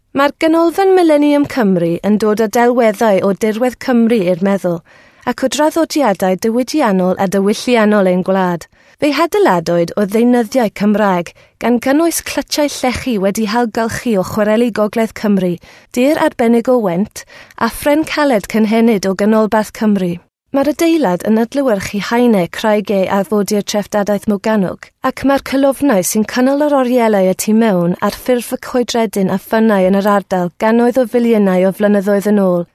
Welsh, Female, 20s-30s